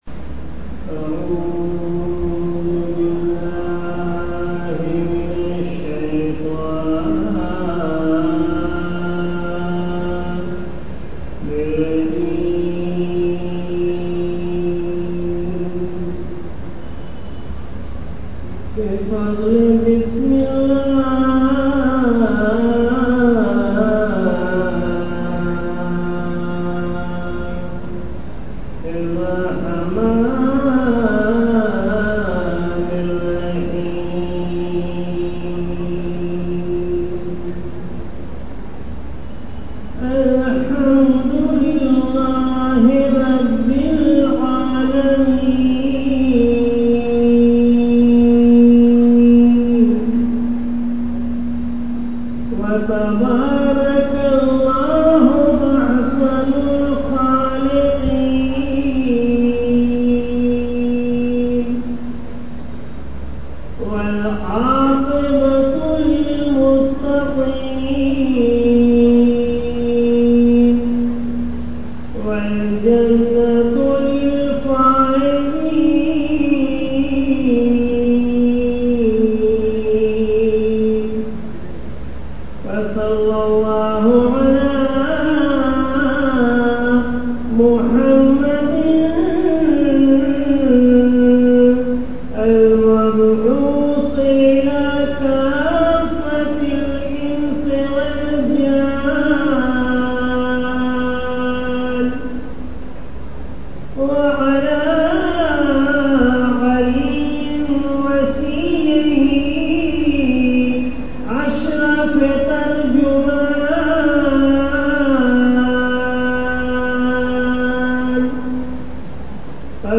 Pehli Raat Jumaadi ul Ulaa 1441 AH - Mo'jezah of Tuffaah-e-Jannat Video Series of Different Aspects of her Life 1440 AH Bayaan of Saiyedato Nisaa il-Aalameen Maulaatona Faatemah az-Zahraa (as) ( audio) Urs Mubaarak Du'aa to remove the ill-effects of Bad Dreams